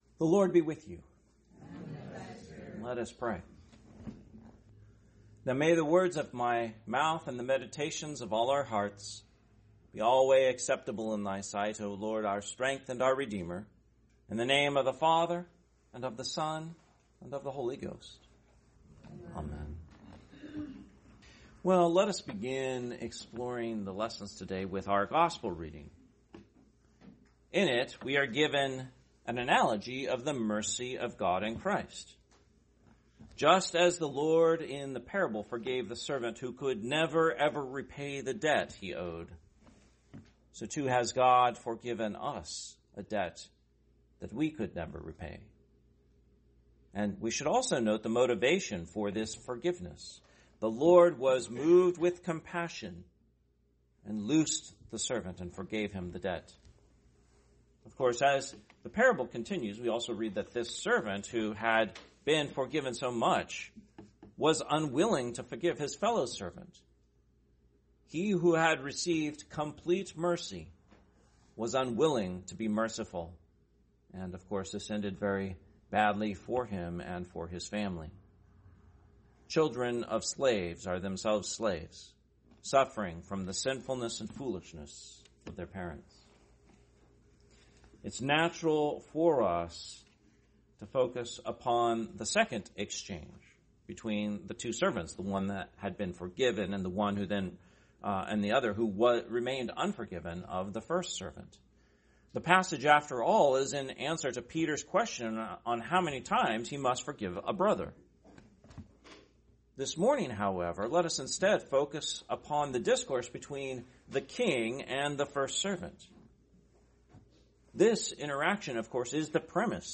Sermon, 22nd Sunday after Trinity, 2025 – Christ the King Anglican Church